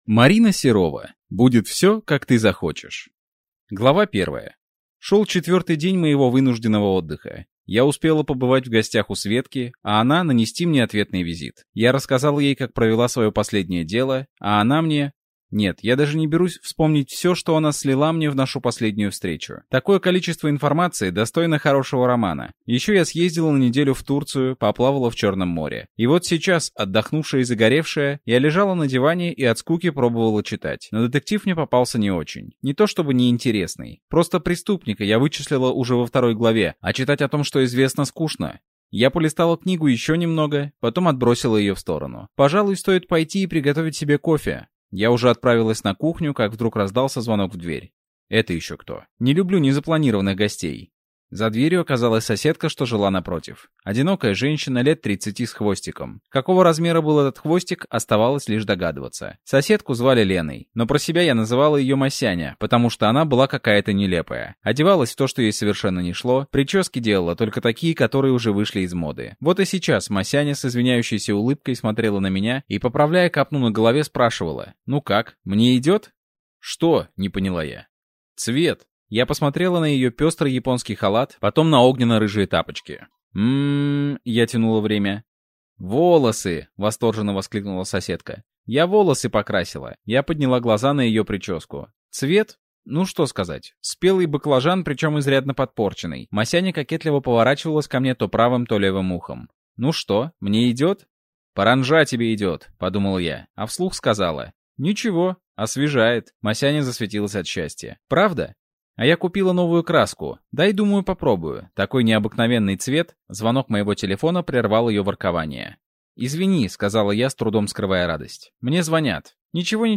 Аудиокнига Будет все, как ты захочешь!